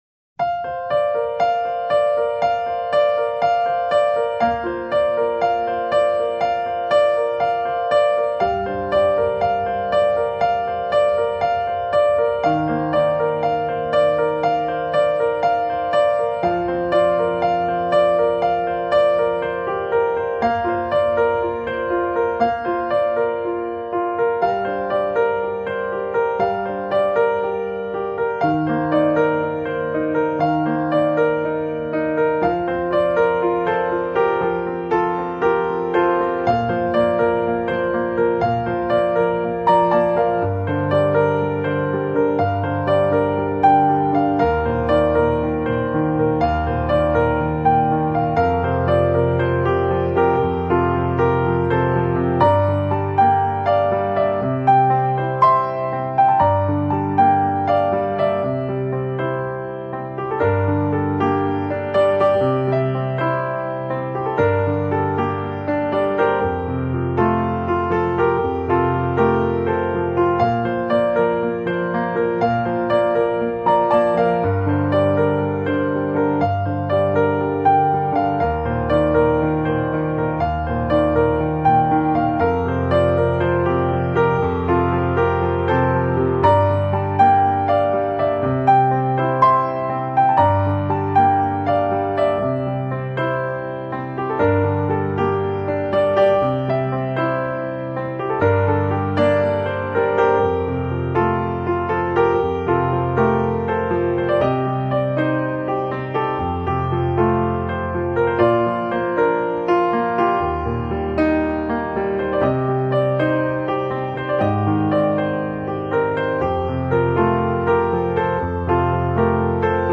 klavir11.mp3